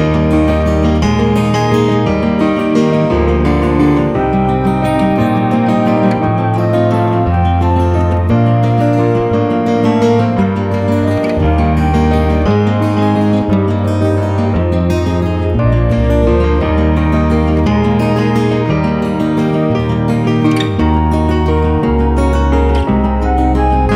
no Piano Pop (1980s) 3:22 Buy £1.50